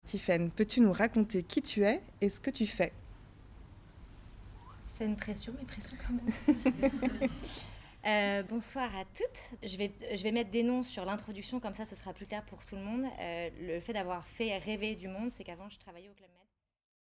L’entretien est un dialogue à deux voix : celle de l’intervieweur et celle de l’interviewé.
Entretien : journaliste / interviewé
1 – Entretien brut